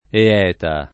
Eeta [ e- $ ta ]